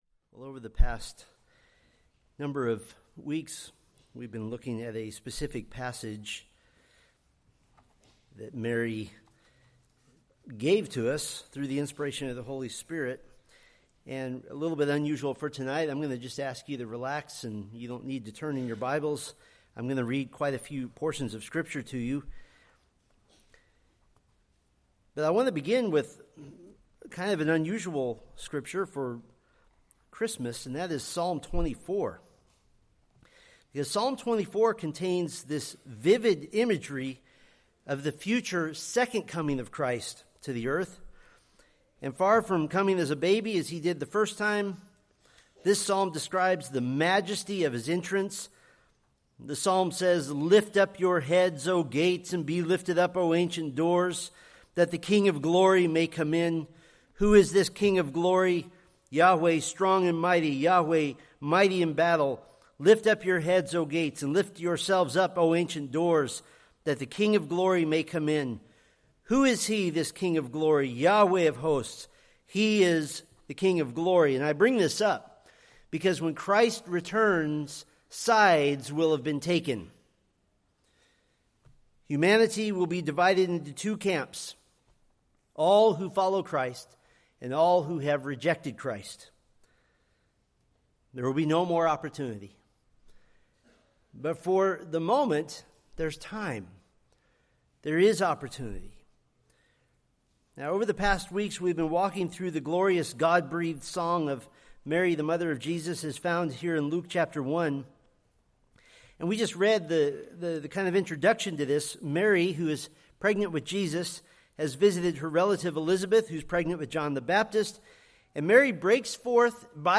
Preached December 24, 2025 from Luke 1:56